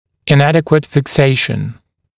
[ɪn’ædɪkwət fɪk’seɪʃn] [-wɪt][ин’эдикуэт фик’сэйшн] [-уит]ненадлежащая фиксация, неправильная фиксация